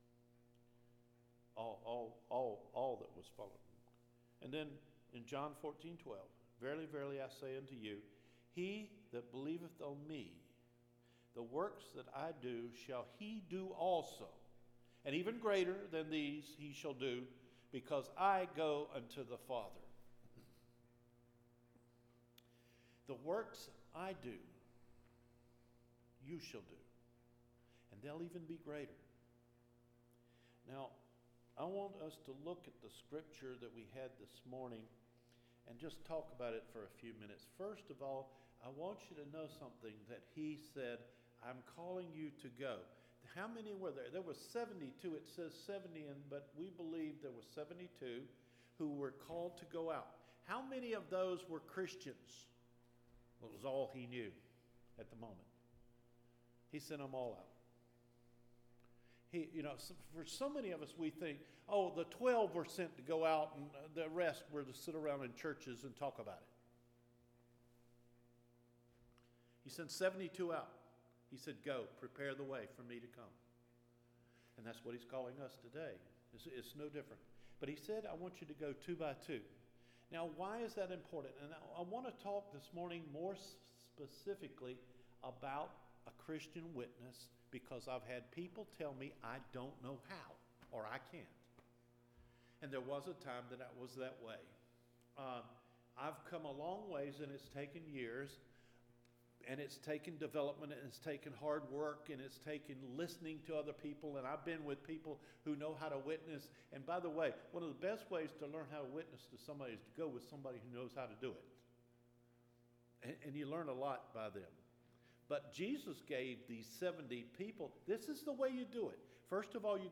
FEBRUARY 21 SERMON – ENCOUNTERING THE KEYS TO A JESUS WITNESS – Cedar Fork Baptist Church